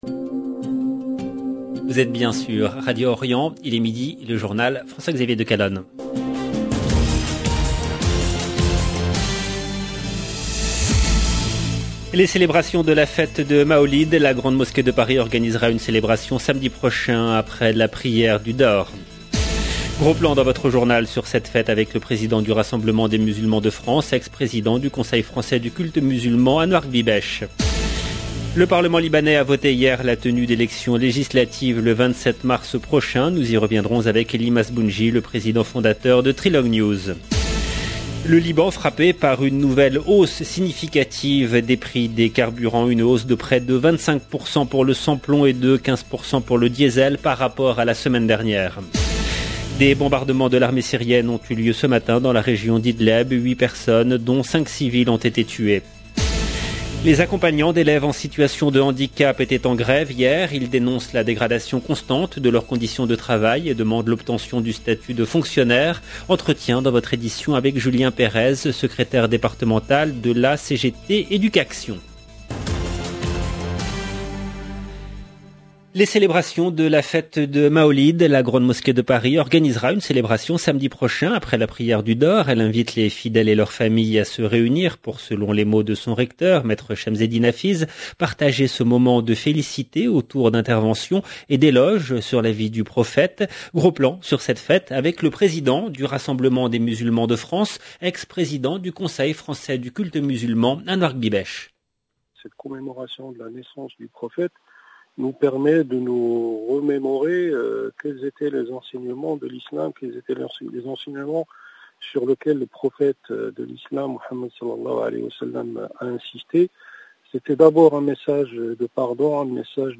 LE JOURNAL DE MIDI EN LANGUE FRANCAISE DU 20/10/2021